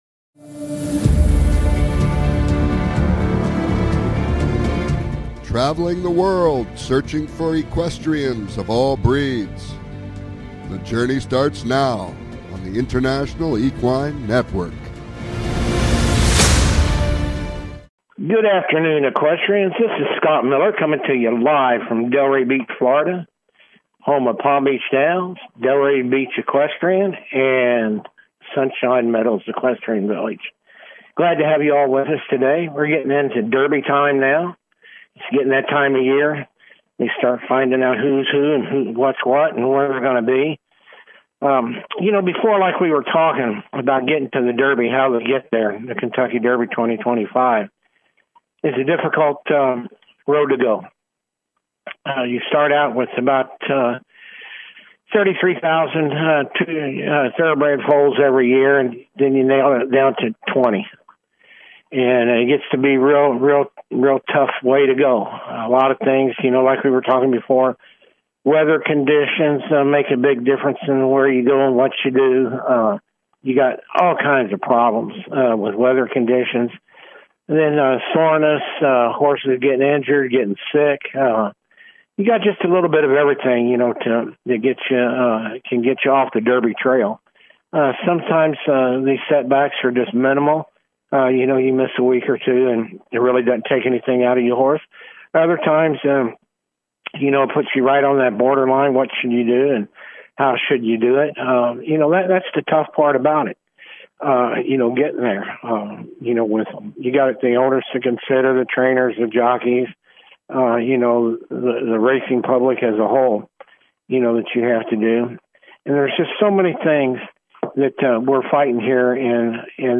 Talk Show
Calls-ins are encouraged!